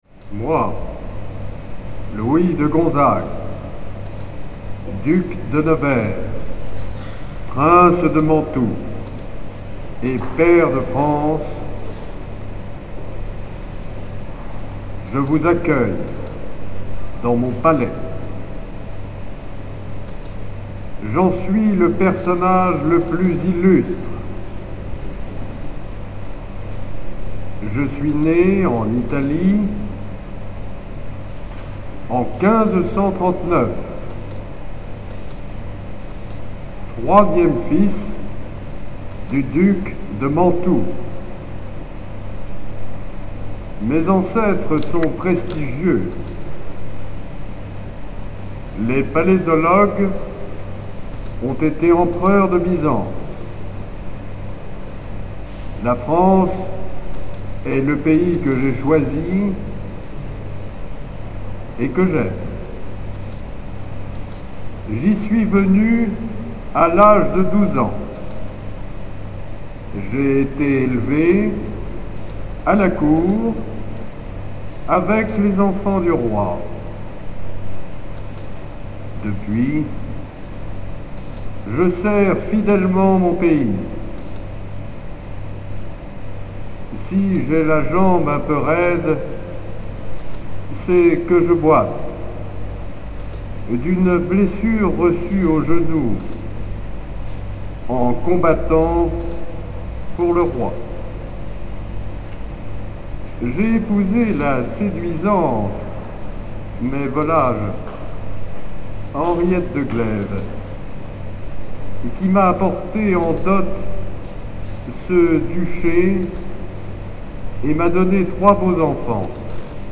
Duc de Nevers - Automate parlant